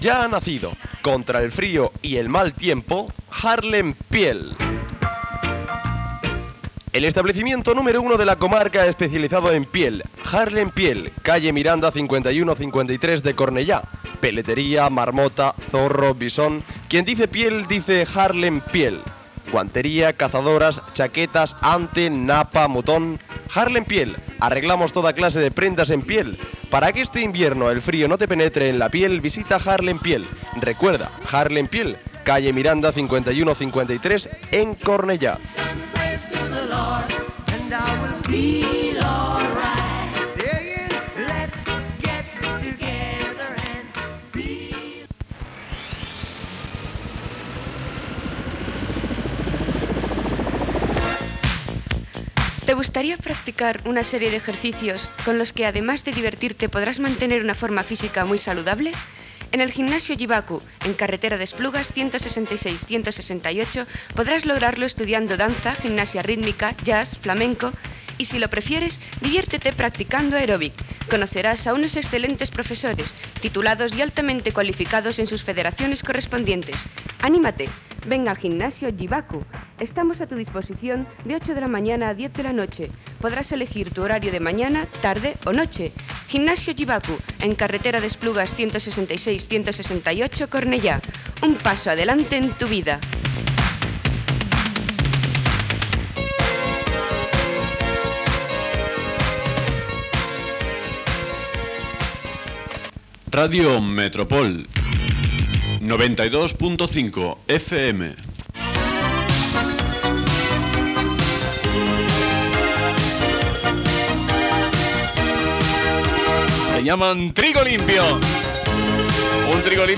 Publicitat, indicatiu i presentació d'un tema musical.